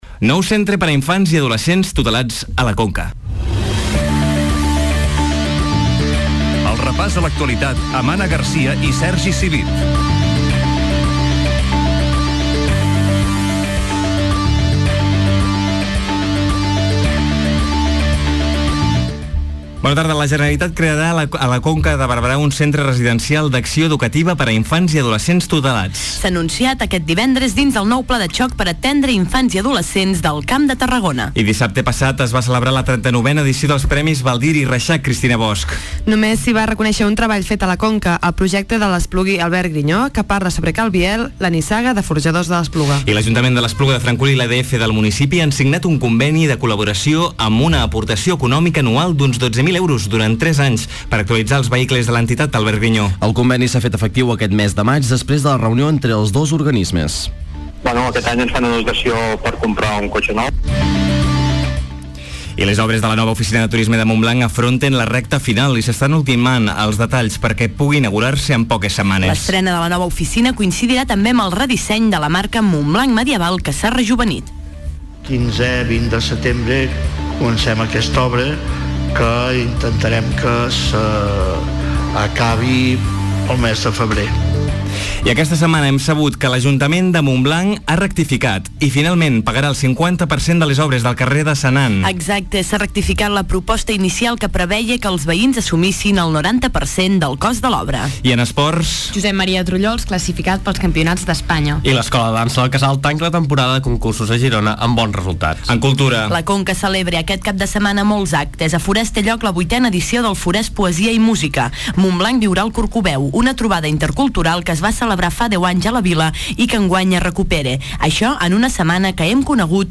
Informatiu cap de setmana on comencem parlant que la Conca tindrà un nou centre per a infants i adolescents a la Conca.